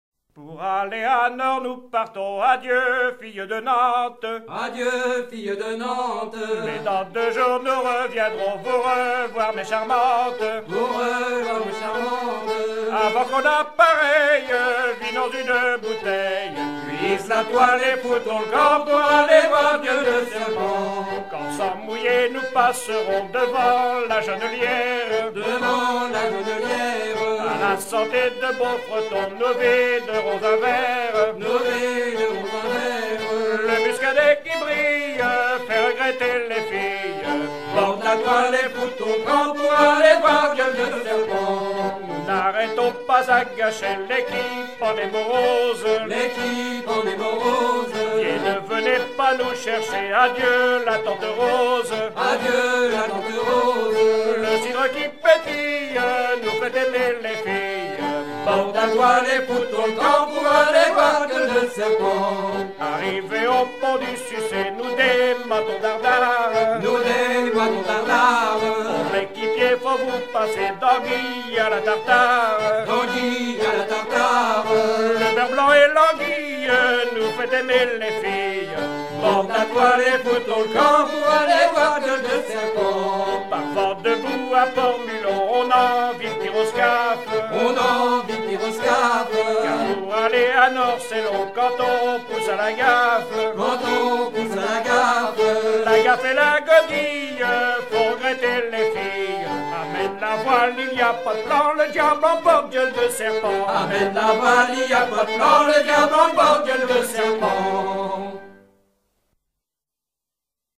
Thème : 10644 - Batellerie
Genre strophique
Pièce musicale éditée